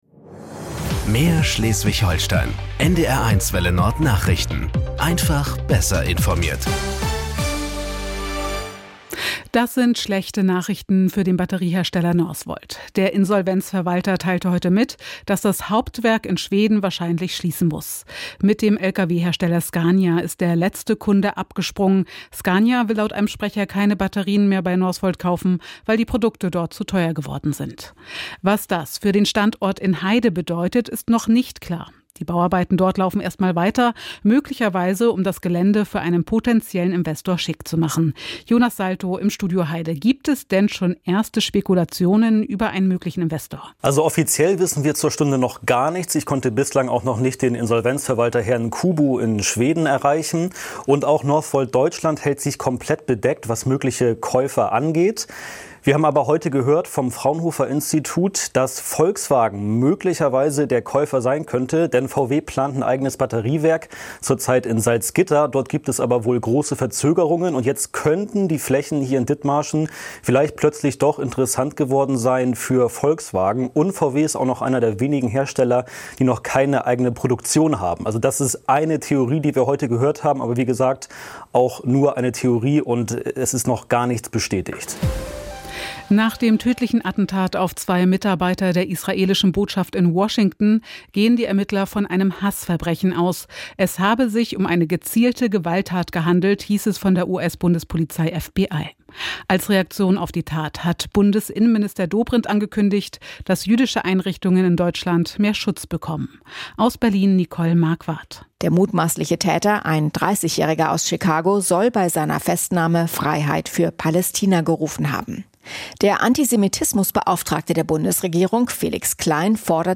… continue reading 4 에피소드 # Tägliche Nachrichten # Nachrichten # NDR 1 Welle Nord